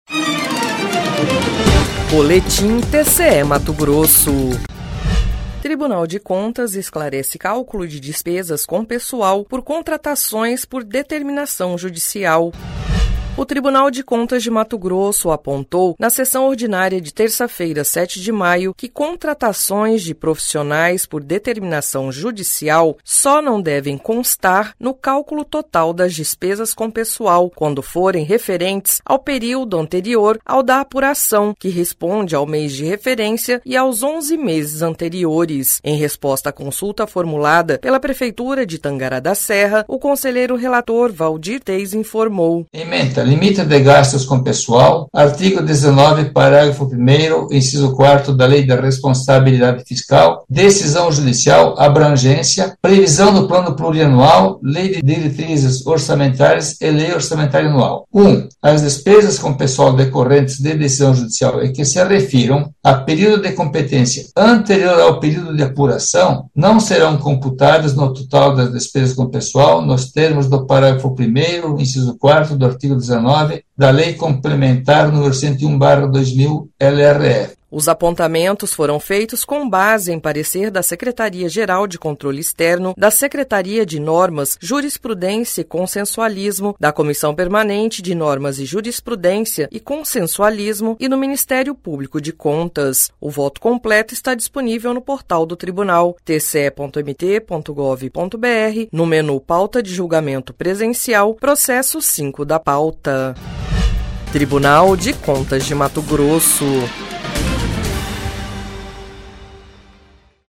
Sonora: Waldir Júlio Teis – conselheiro do TCE-MT